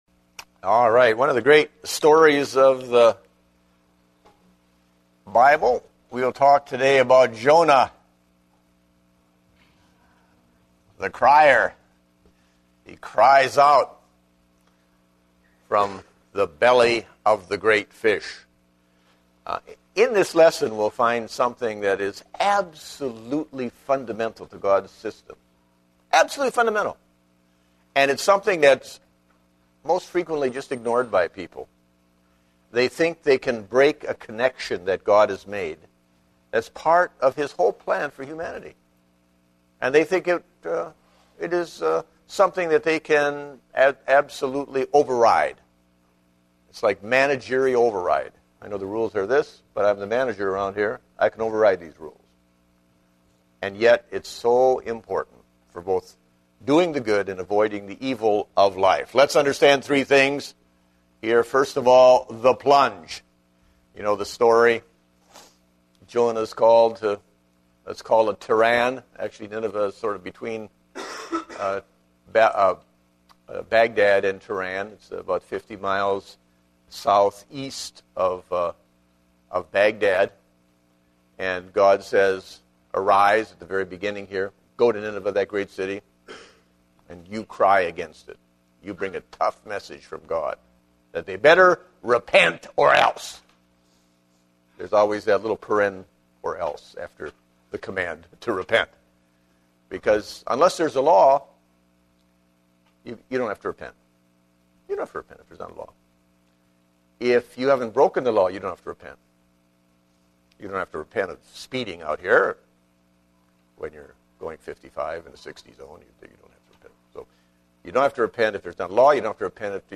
Date: October 10, 2010 (Adult Sunday School)